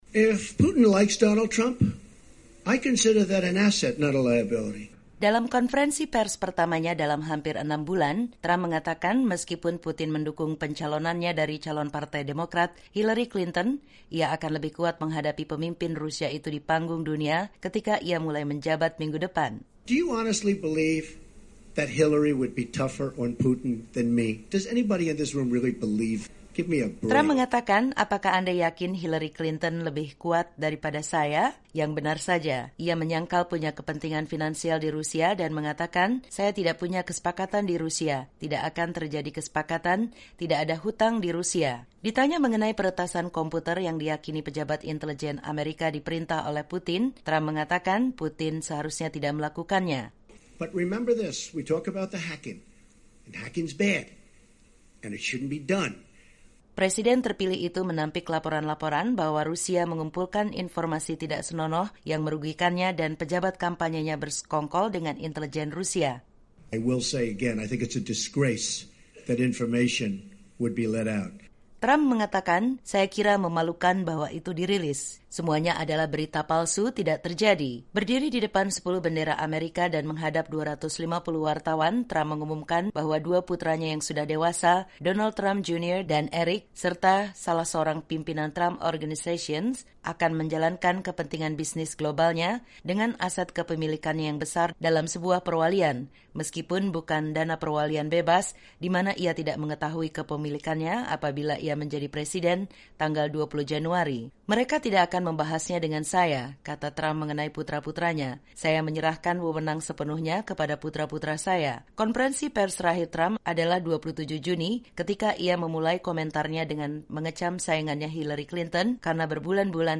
Konferensi Pers Donald Trump